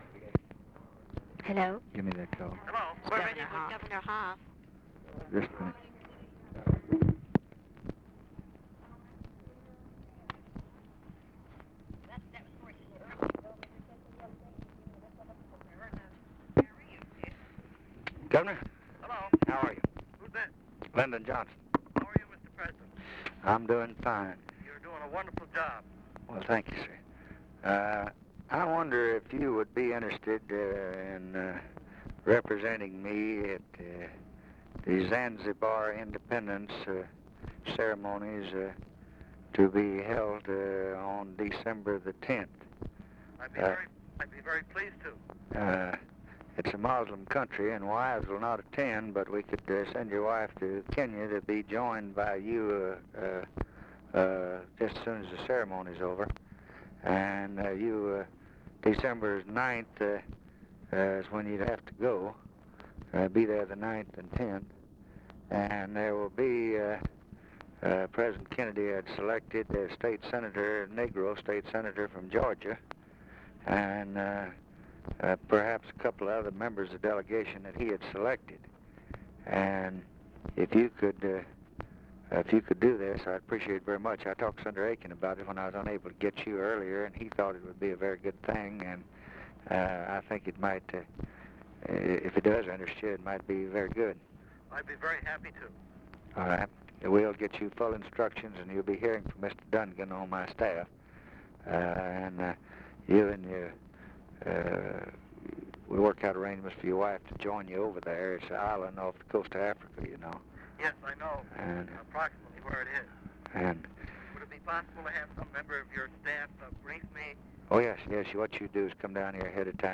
Conversation with PHILIP HOFF, November 30, 1963
Secret White House Tapes | Lyndon B. Johnson Presidency Conversation with PHILIP HOFF, November 30, 1963 Rewind 10 seconds Play/Pause Fast-forward 10 seconds 0:00 Download audio Previous Conversation with WILLIAM MCC.